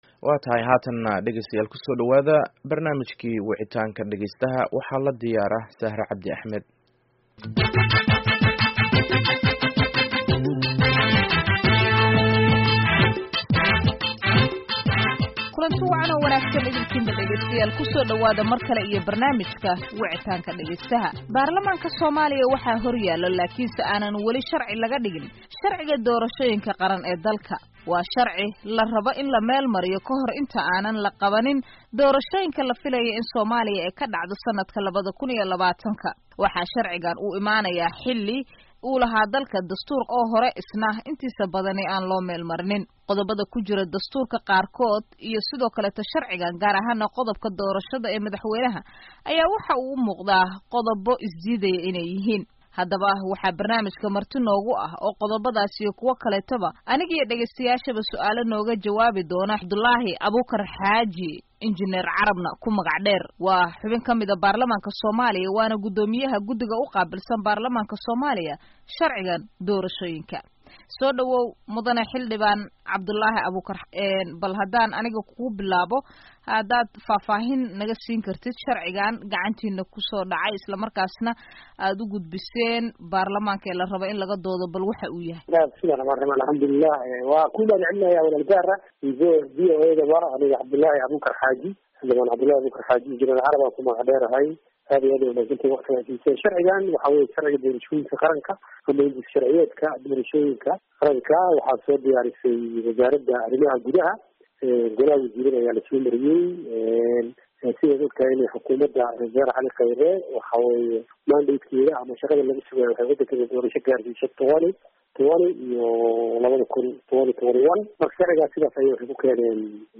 Barnaamijka Wicitaanka Dhageystaha ee toddobaadkan waxaa marti ku ah xildhibaan Cabdullaahi Abuukar Xaaji (Injineer Carab) oo ka jawaabaya su'aalaha dhageystayaasha VOA ee la xiriira ansixinta sharciga Isgaarsiinta Somalia